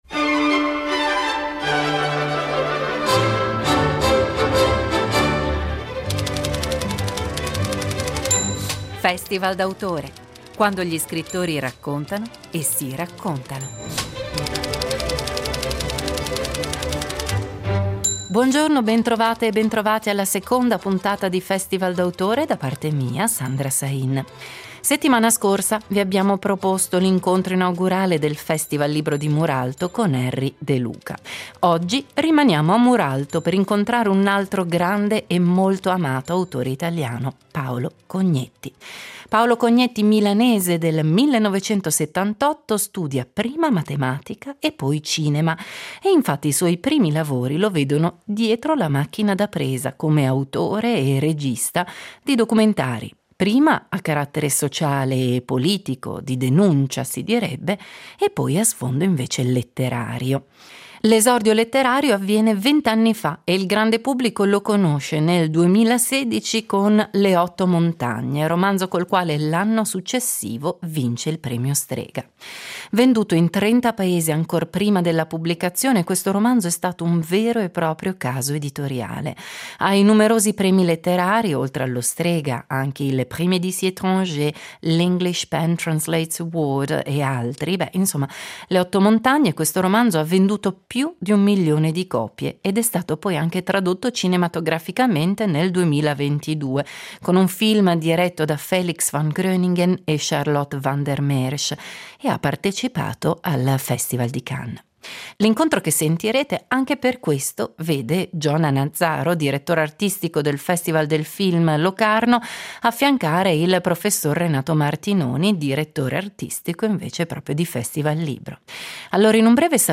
Paolo Cognetti al FestivalLibro Muralto